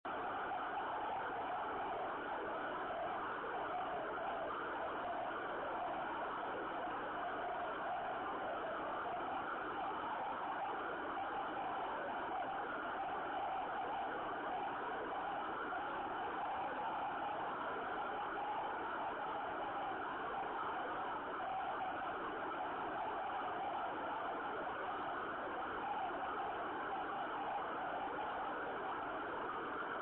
Pozorně si poslechněte, jak vypadají signály z pásma 24GHz, které přišly z Texasu odrazem od Měsíce. Charakteristický je jejich syčivý tón, vzniklý odrazy - vlnová délka je 1,5cm!